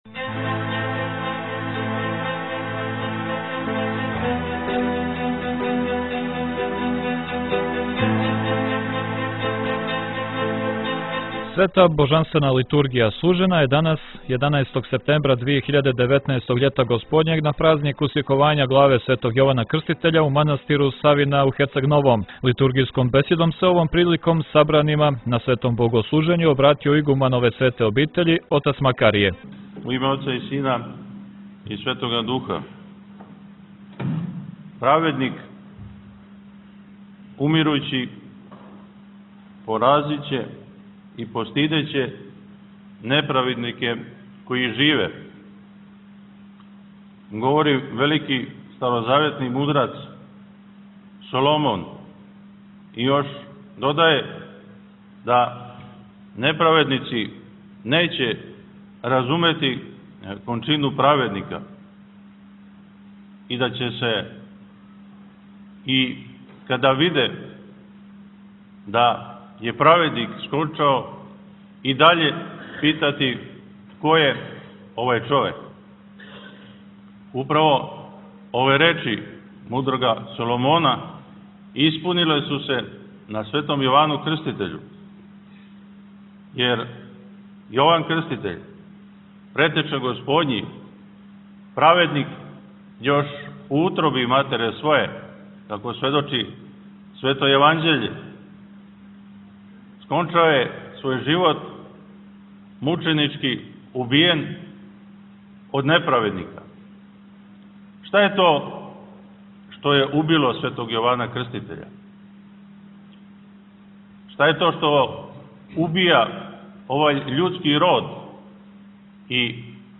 Поводом празника Усјековања главе св Јована Крститеља Света литургија служена је данас у манастиру Савина у Херцег Новом